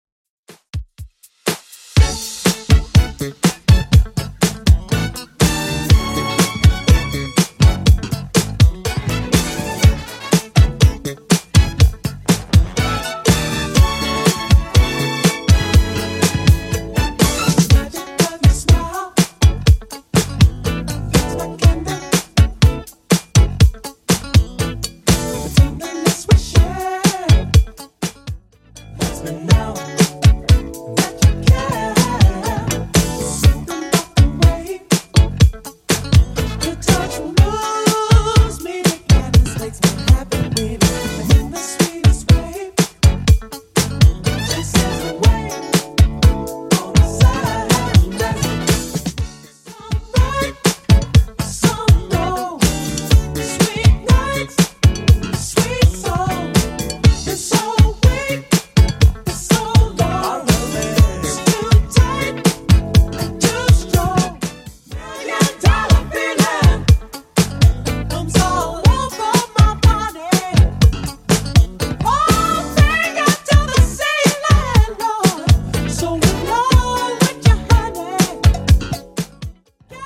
Genre: 80's Version: Clean BPM: 118